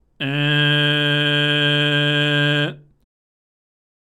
喉頭は巨人の状態でグーで鼻口